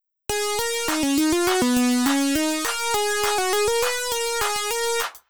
何かまだ続きそうな、少なくともこのままは終われなさそうな雰囲気がありますよね？
最後の音は「ラ♯」になっています。